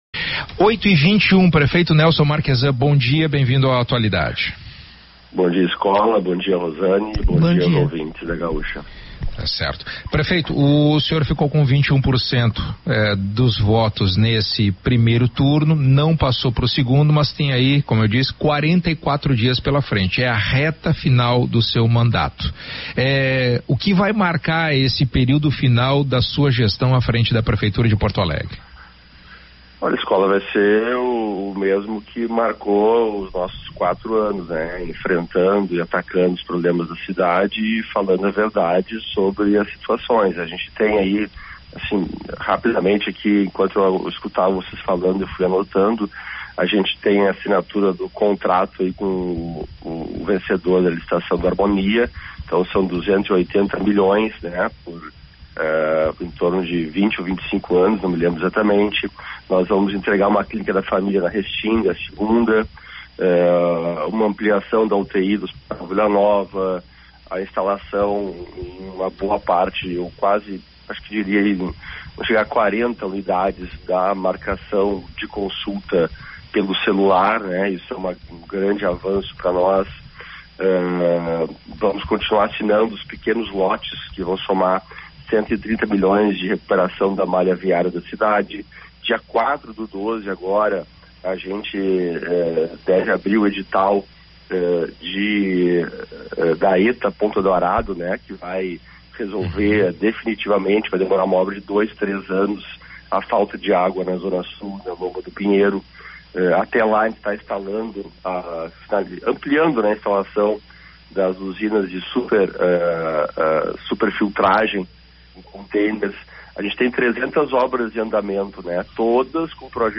Entrevista com Prefeito Nelson Marchezan